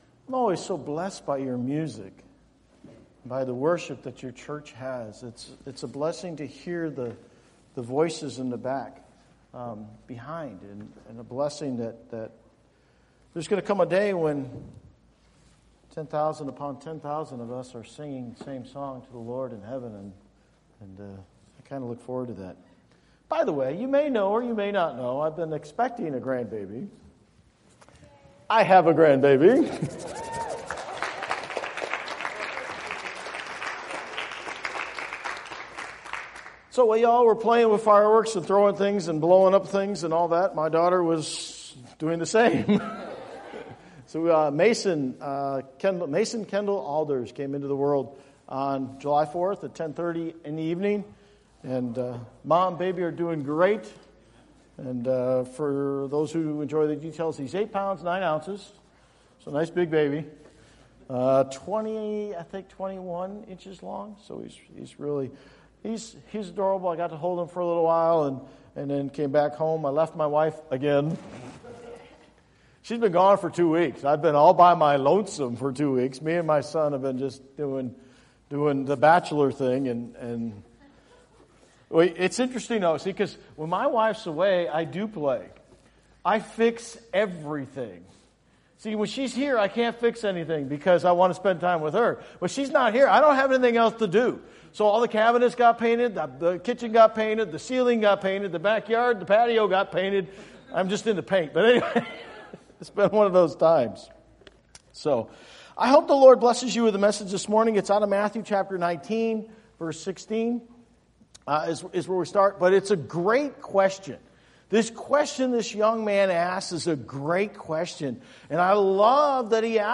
Bible Text: Matthew 19:16-26 | Preacher